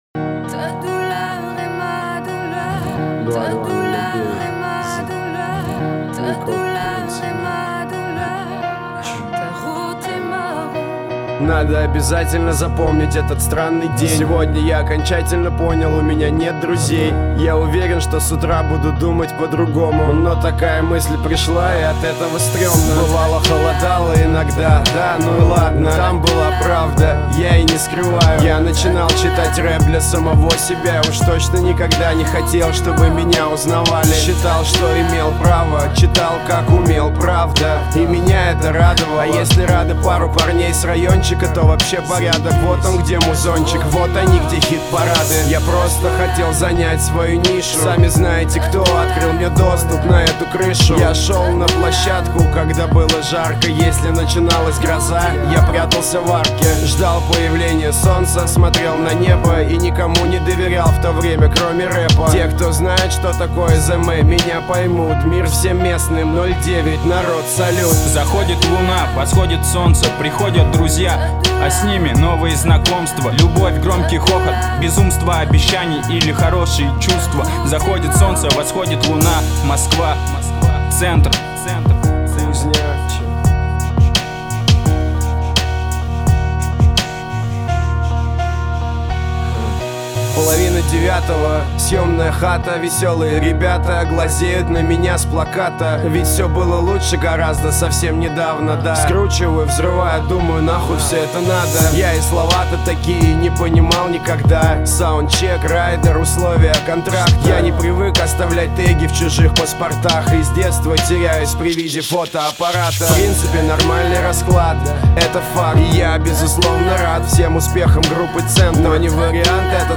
Главная » Файлы » Стиль музыки » Русский рэп